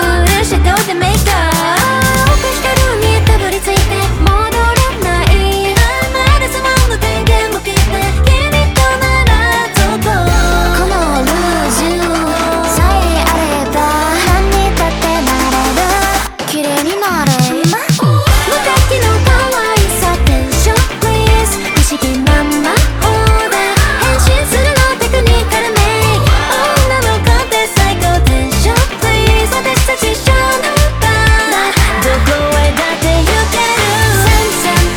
Жанр: Поп музыка
J-Pop